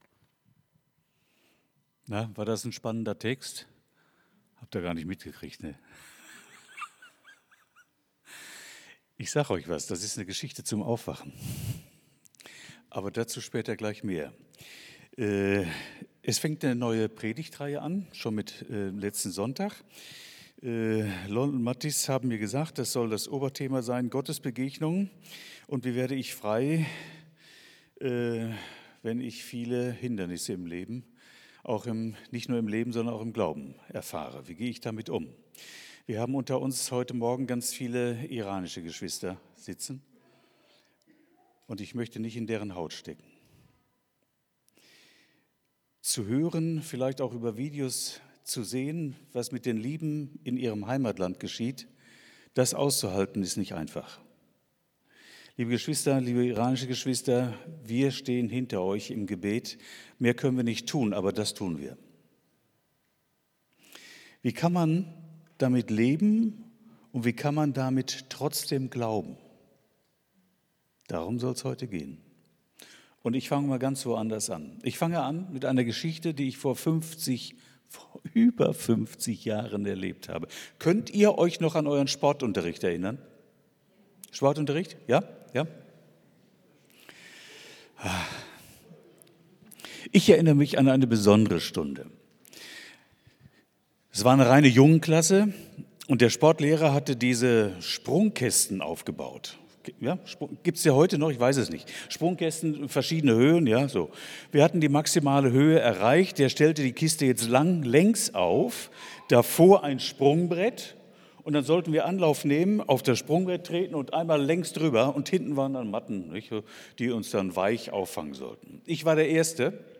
Predigt vom 08.02.2026